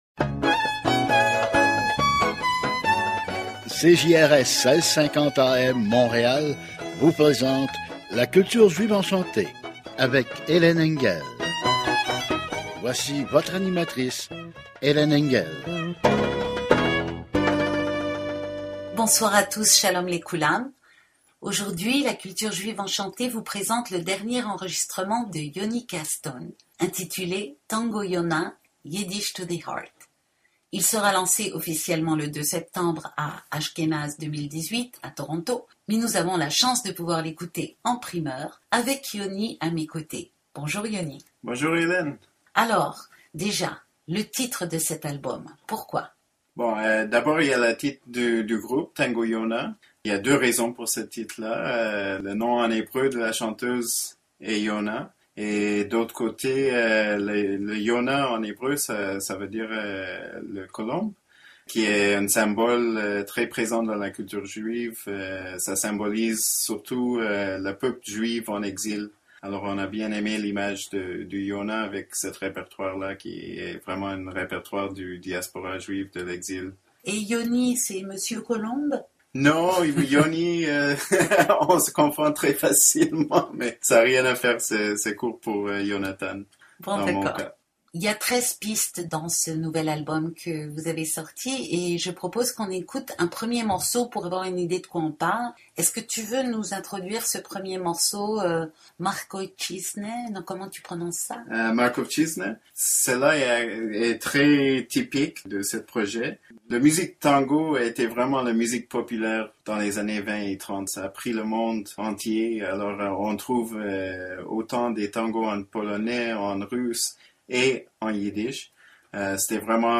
LA CULTURE JUIVE ENCHANTÉE – 19 aout 2018 – RADIO SHALOM Montréal (1650 AM)